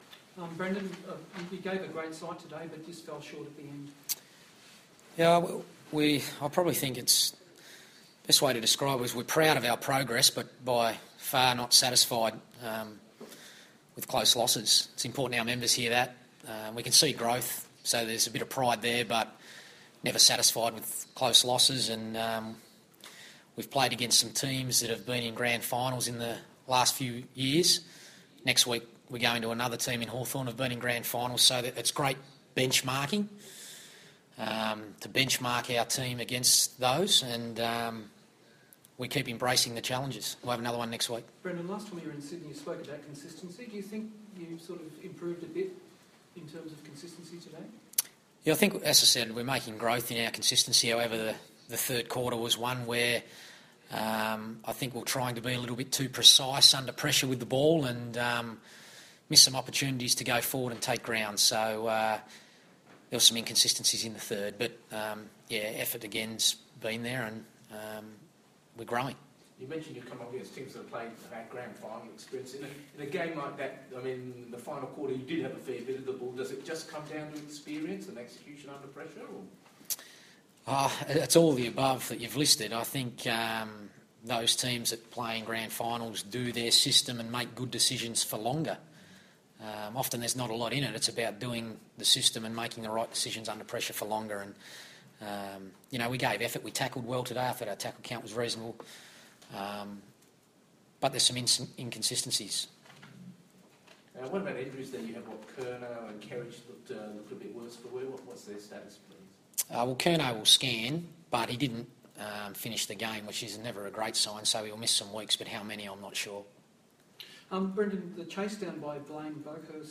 Carlton coach Brendon Bolton fronts the media after the Blues' six-point loss to Sydney at the SCG.